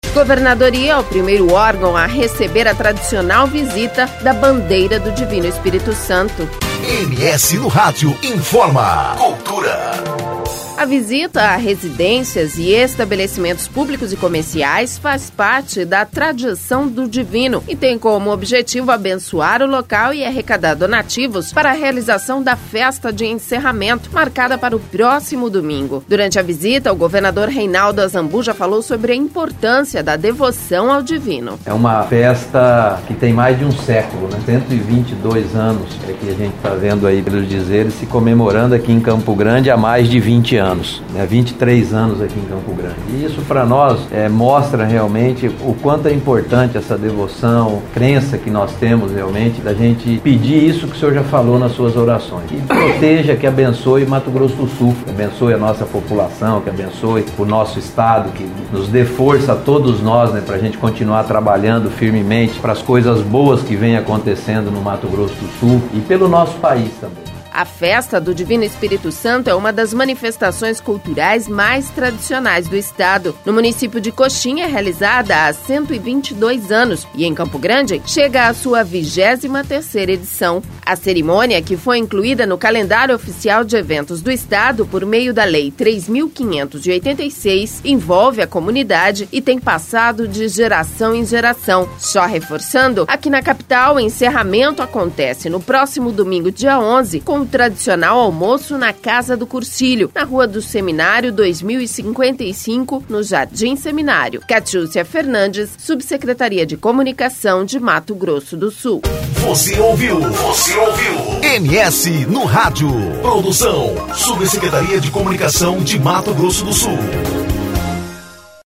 Durante a visita o Governador Reinaldo Azambuja falou sobre a importância da devoção ao Divino.